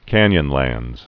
(kănyən-lăndz)